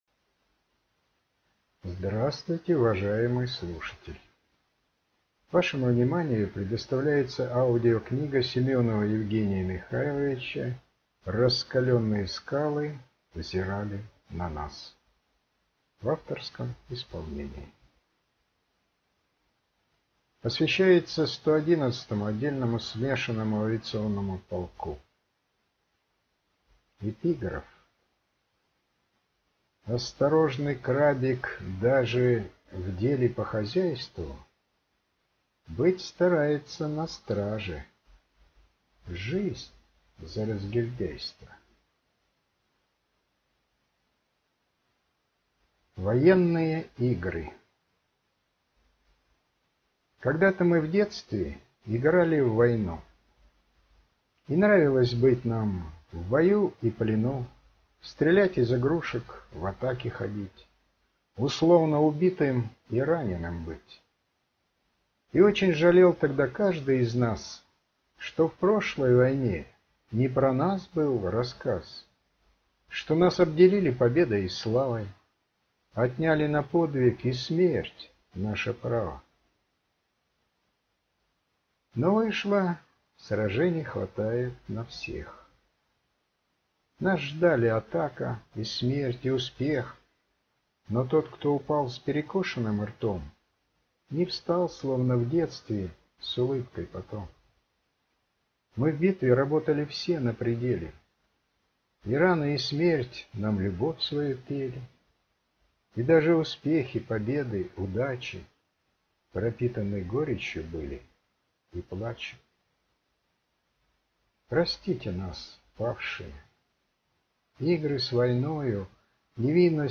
Аудиокнига «Раскалённые» скалы взирали на нас | Библиотека аудиокниг